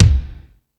Lotsa Kicks(30).wav